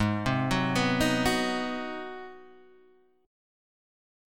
G#7#9 chord {4 3 4 4 4 2} chord